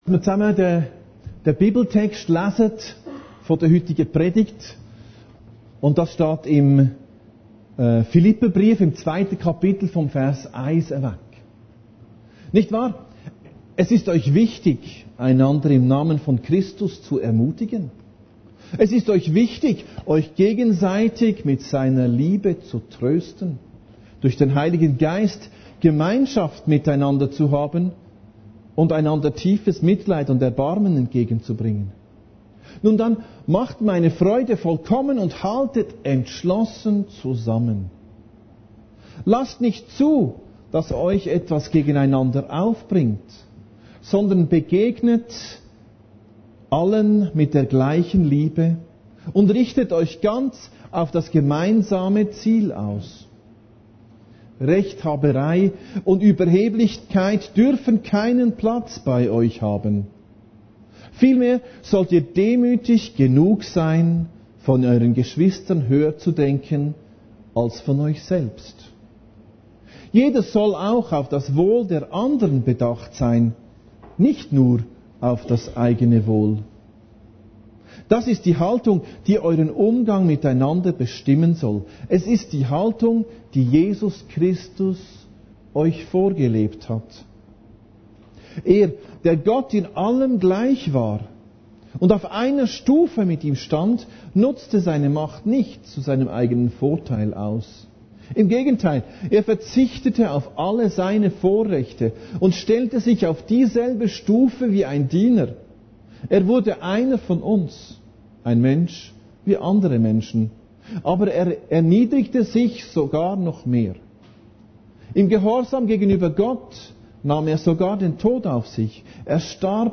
Predigten Heilsarmee Aargau Süd – Gemeinschaft im Fokus